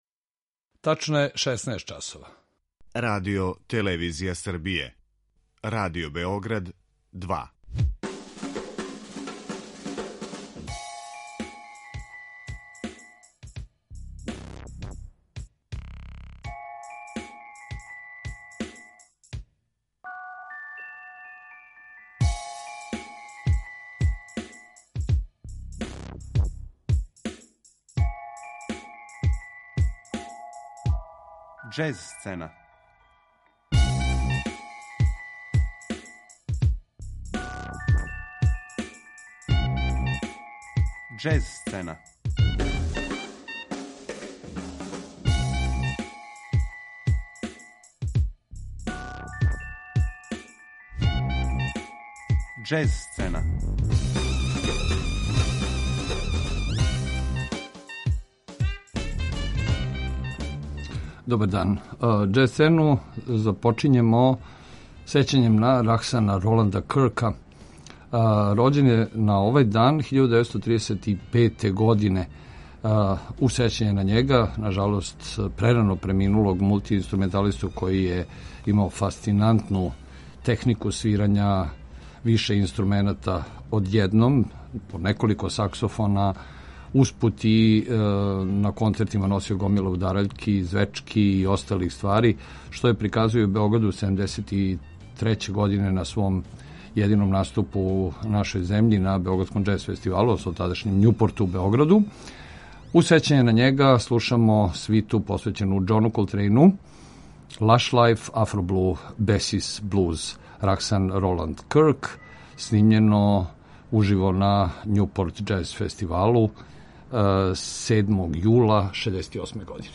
тромбониста
у фанк-џез маниру